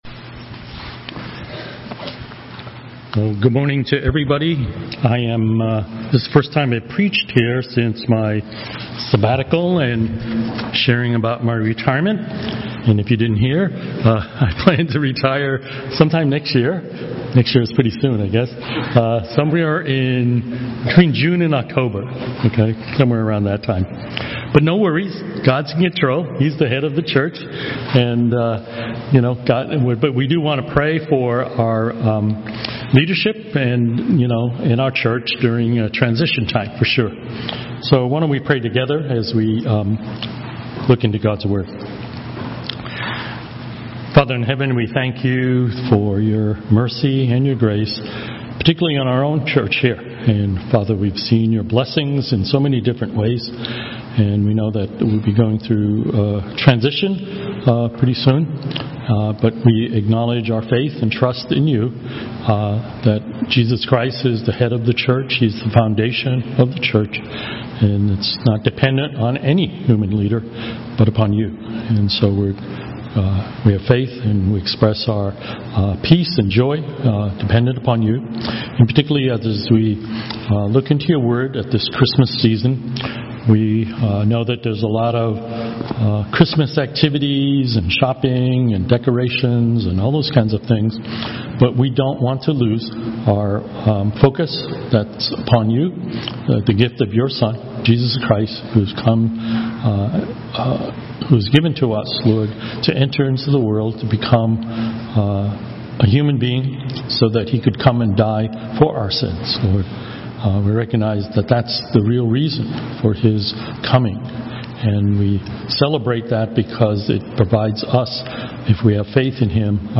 Sermons - Page 18 of 74 | Boston Chinese Evangelical Church